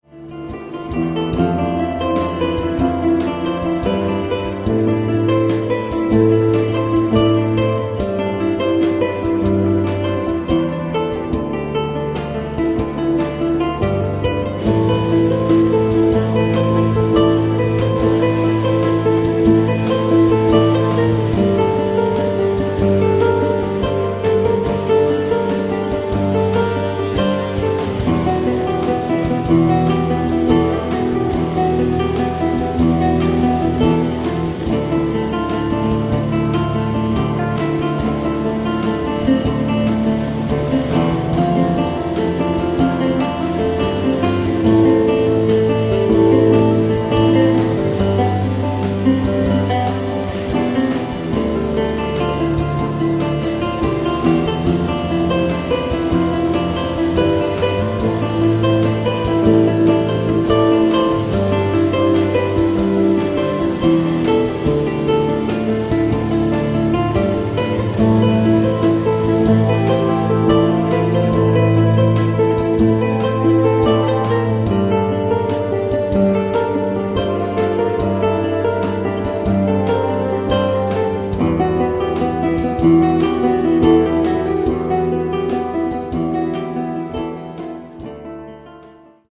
lute & oud player from Japan
Contemporary
Progressive Metal